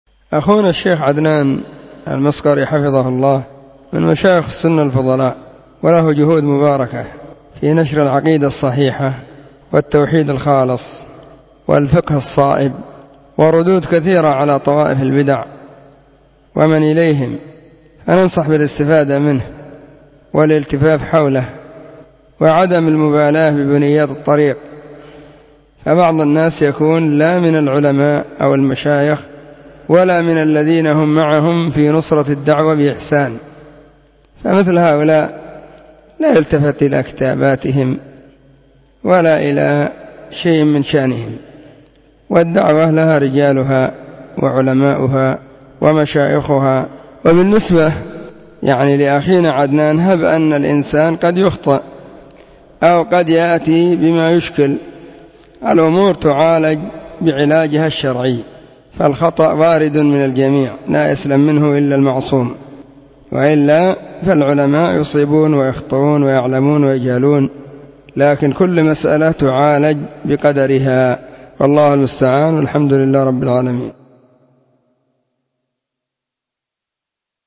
📢 مسجد – الصحابة – بالغيضة – المهرة، اليمن حرسها الله.